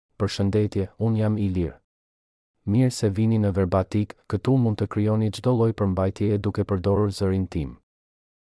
MaleAlbanian (Albania)
IlirMale Albanian AI voice
Voice sample
Male